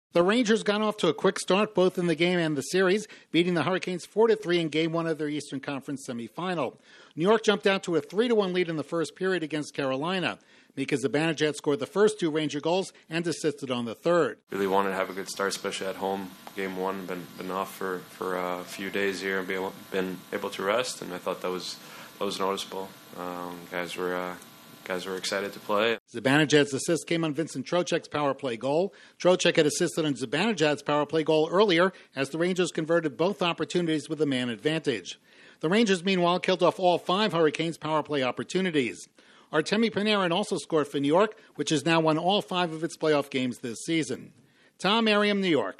The Rangers strike first in their series with the Hurricanes. Correspondent